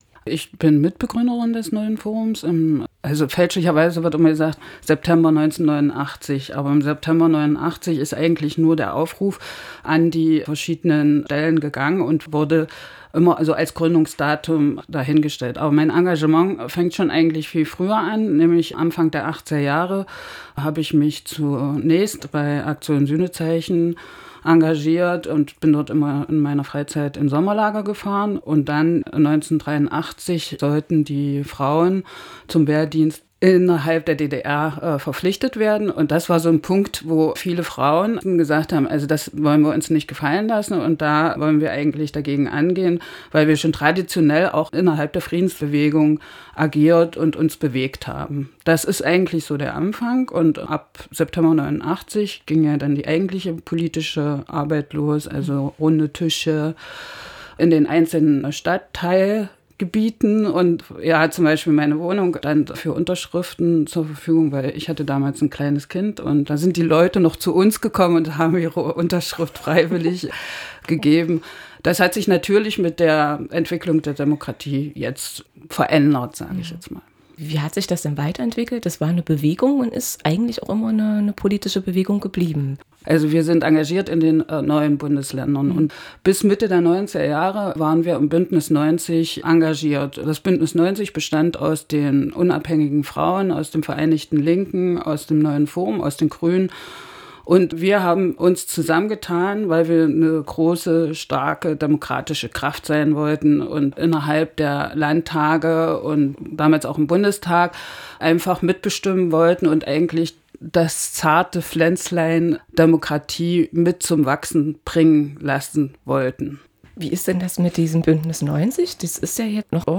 Grund genug für uns mit einem Mitglied der ersten Stunde des NEUEN FORUMs zu sprechen. Sabine Wolff ist engagierte Stadträtin in Halle. Wir sprachen mit ihr über die juristischen Hürden im Wahlkampf 2014. Zu Beginn jedoch ein wenig Geschichte der Organisation.